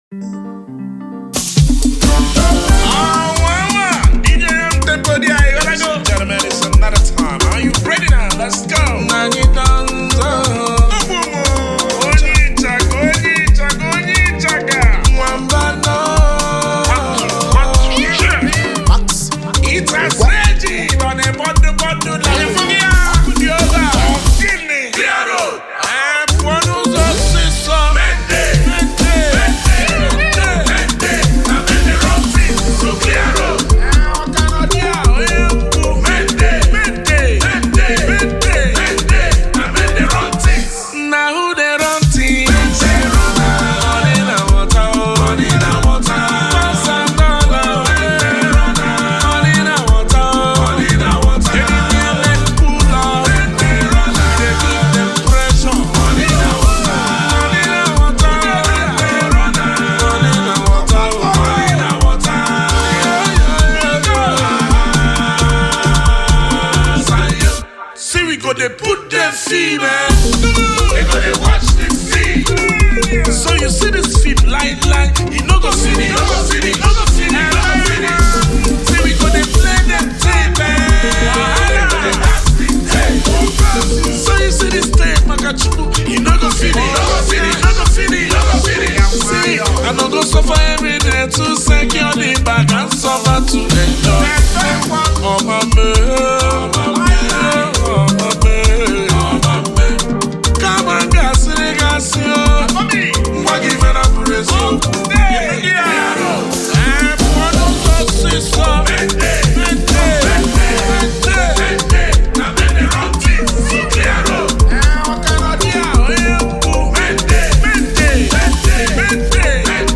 February 18, 2025 Publisher 01 Gospel 0
afrobeat & highlife